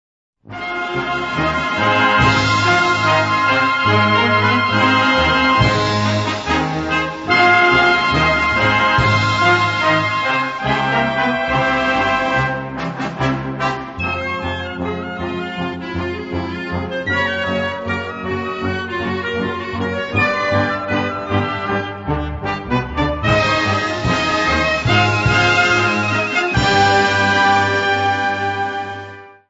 Gattung: Konzertmusik
Besetzung: Blasorchester